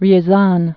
(ryĭ-zän)